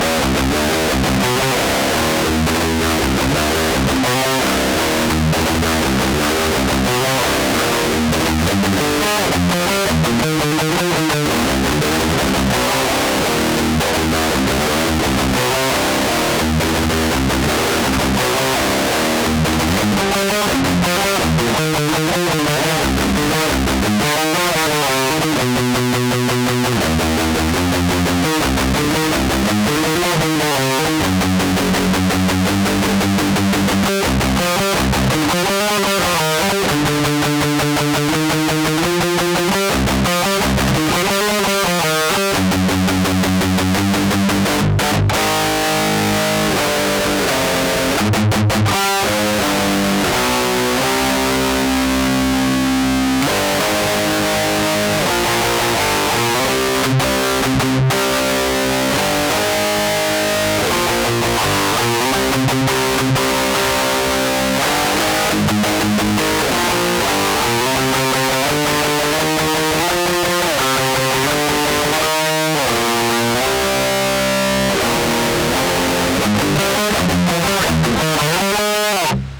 запиите плиз в линию для импульсов у кого что получилось...
peavey-preamp-without-cab.wav